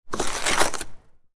Car Glass Scraping Sound Effect
Add realism to your project with a car windshield scraping sound effect. Perfect for videos, games, and winter scenes, this crisp audio captures the authentic sound of glass being scraped.
Car-glass-scraping-sound-effect.mp3